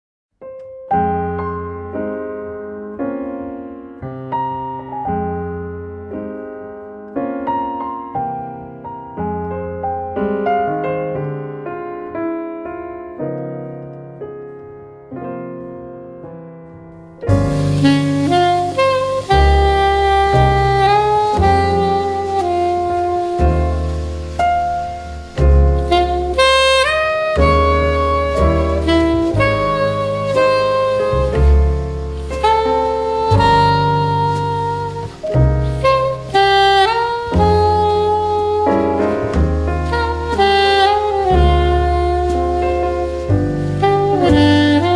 2015年9月2、3、4日 東京録音